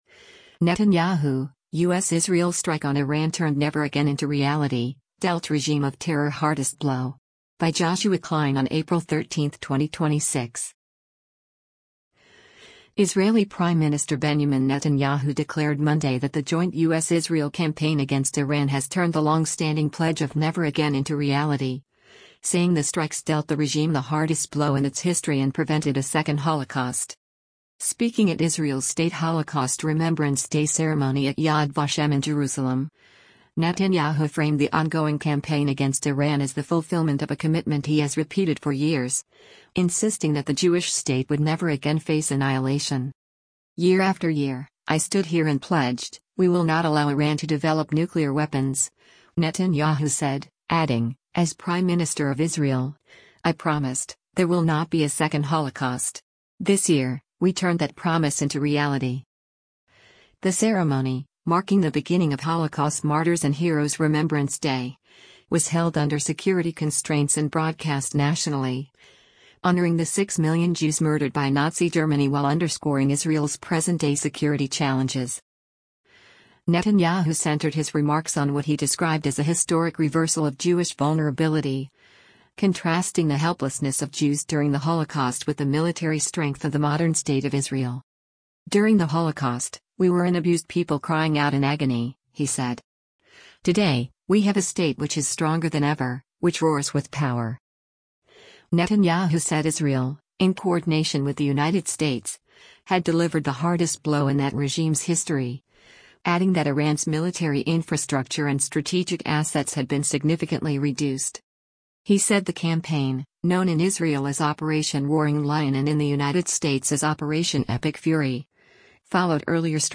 Speaking at Israel’s state Holocaust Remembrance Day ceremony at Yad Vashem in Jerusalem, Netanyahu framed the ongoing campaign against Iran as the fulfillment of a commitment he has repeated for years, insisting that the Jewish state would never again face annihilation.